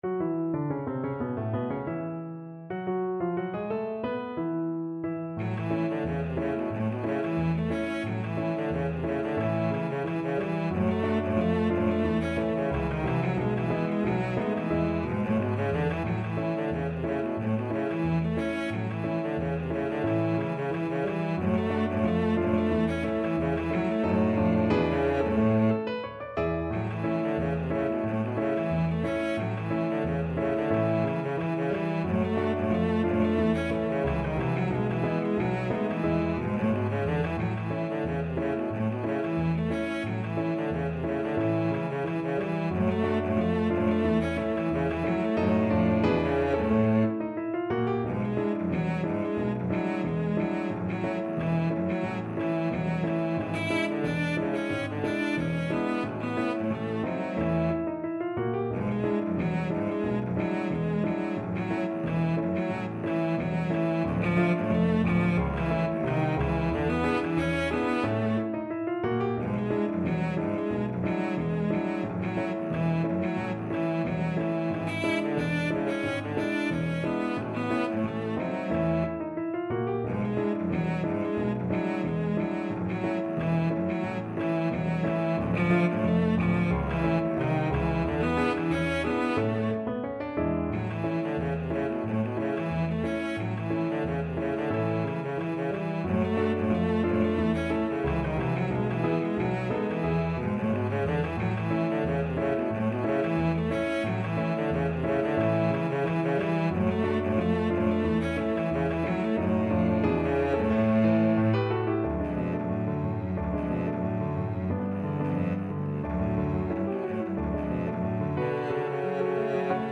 Cello
A major (Sounding Pitch) (View more A major Music for Cello )
2/4 (View more 2/4 Music)
Slow march tempo Slow March tempo. = 90
D3-E5
Jazz (View more Jazz Cello Music)